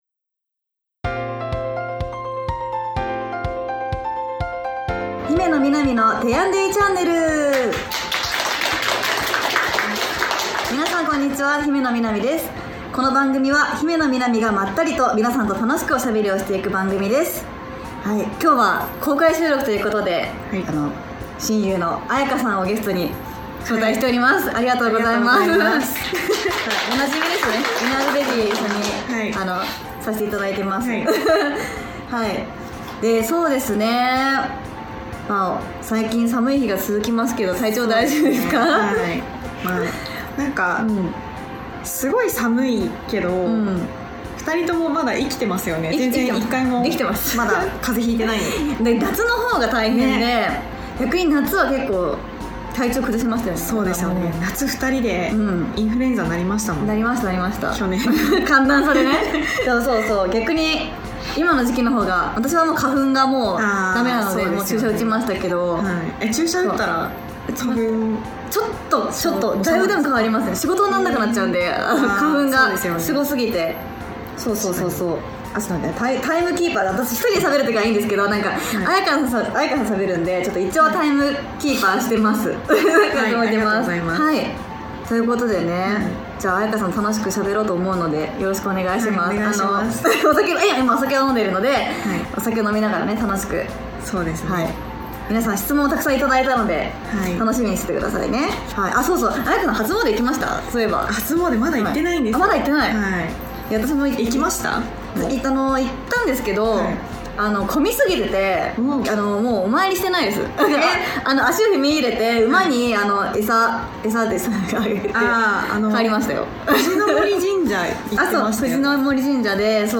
1月に番組初となる公開収録が開催されました♪